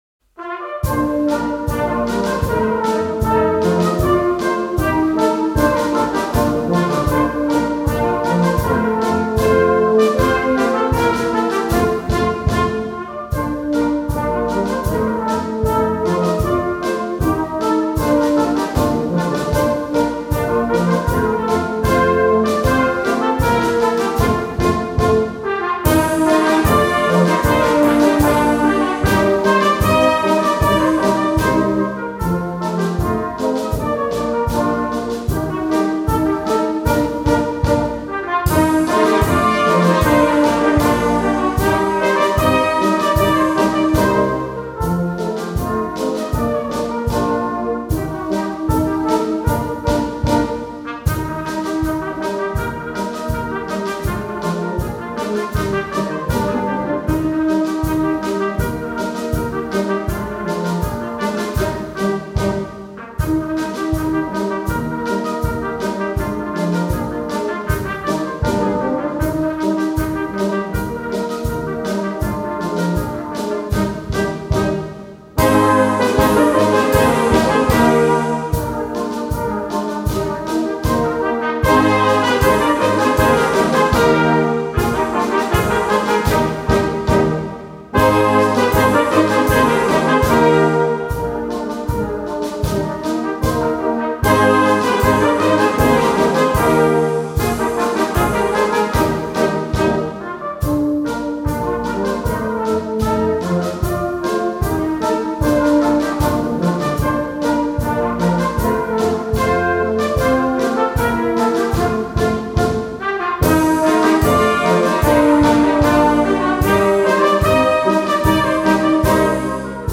Schottisch